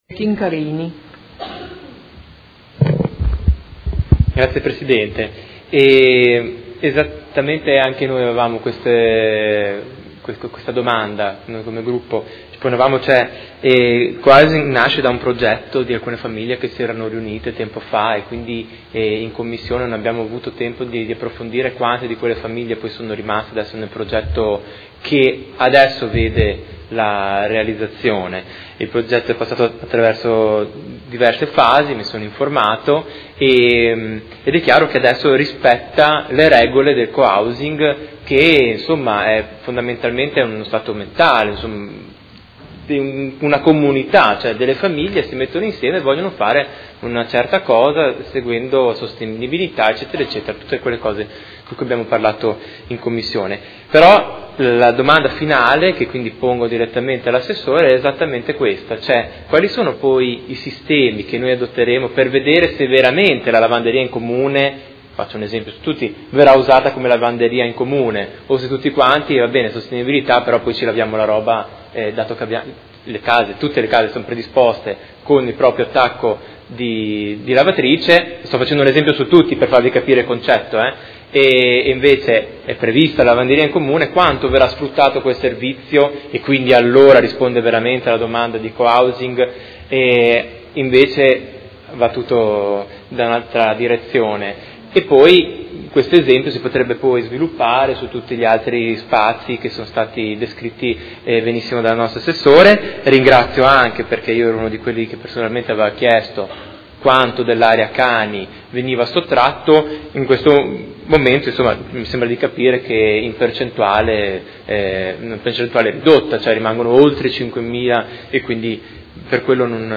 Marco Chincarini — Sito Audio Consiglio Comunale